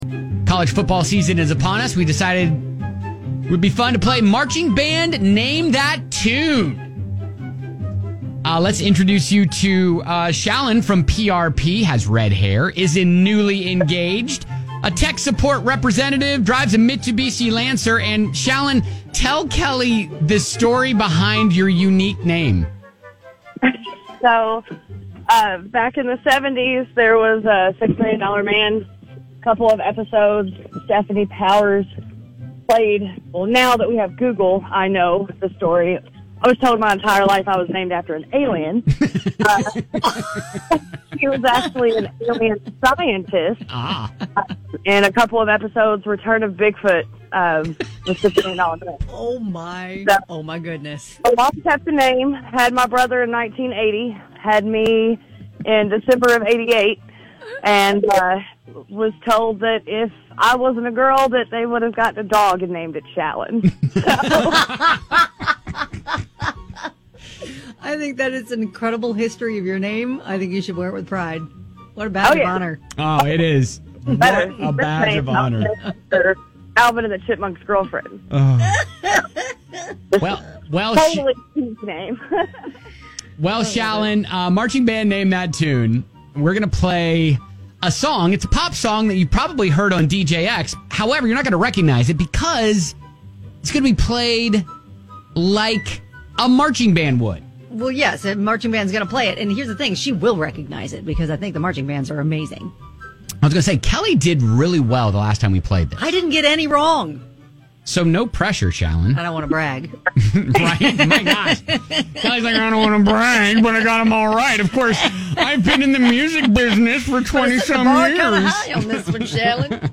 A marching band plays a pop song, you guess the song and win!